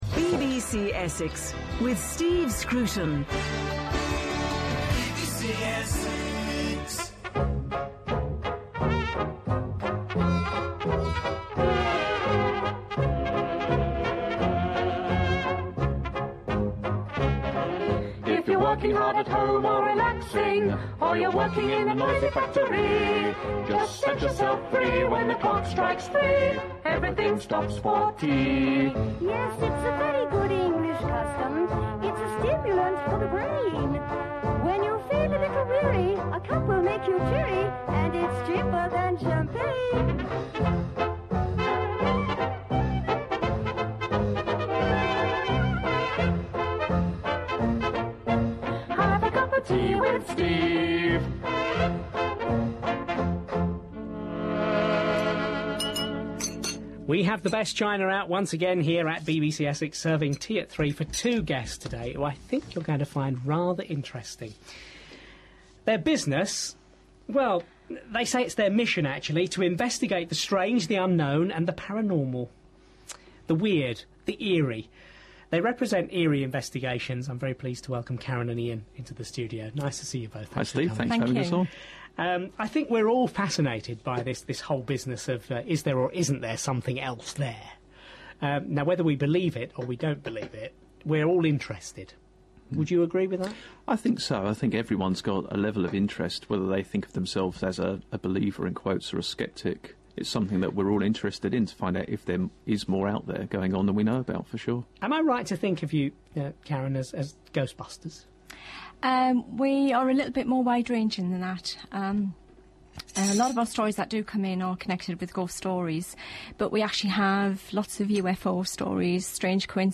Eerie investigations radio interview, aired on BBC Essex, August 22nd 2005